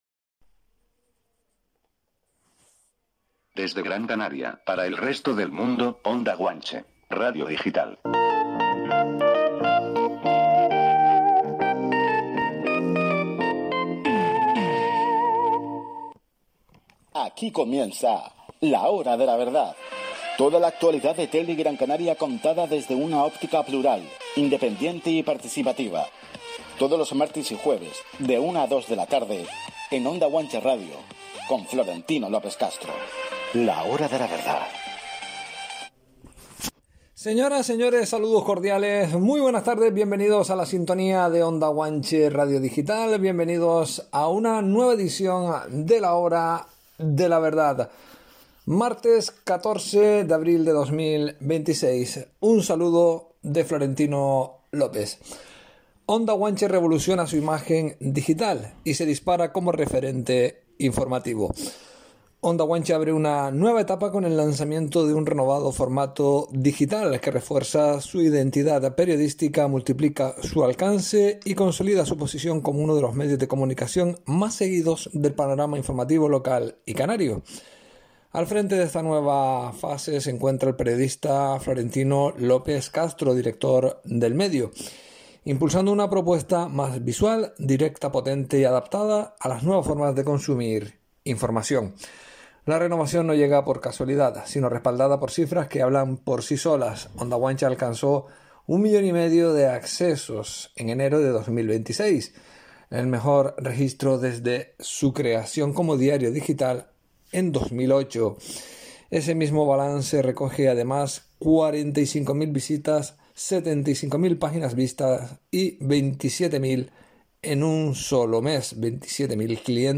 Una entrevista que no dejará a nadie indiferente…